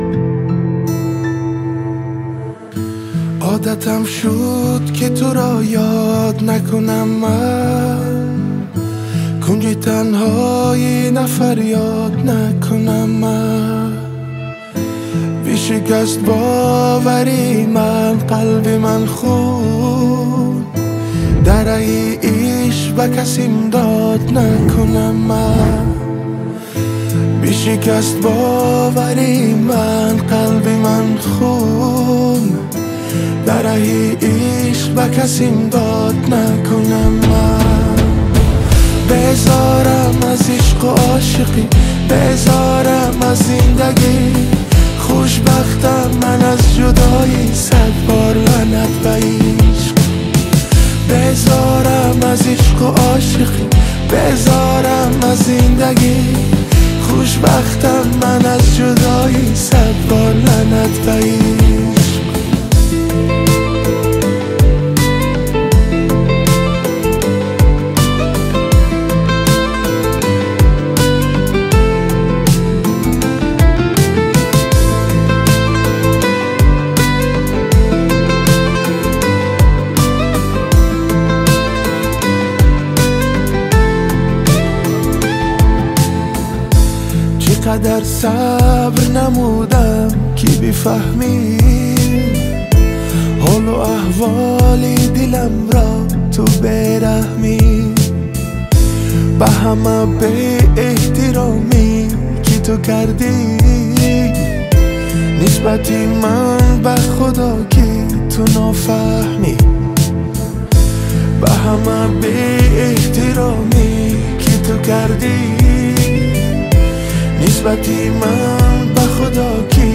Музыка / 2026-год / Таджикские / Поп / Прочее